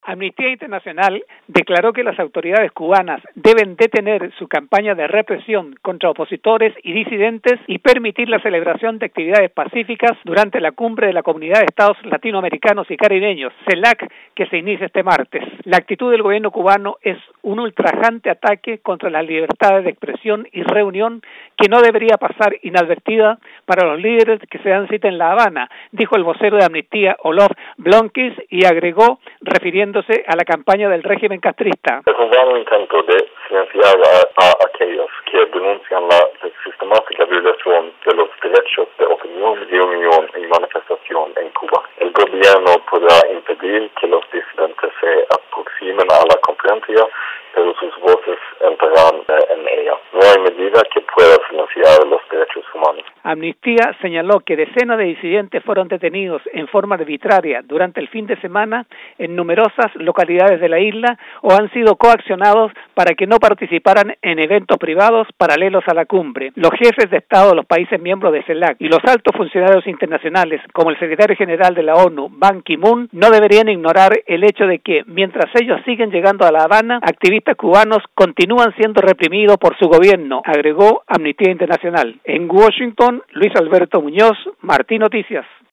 reporta...